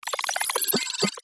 Computer Calculations 1.wav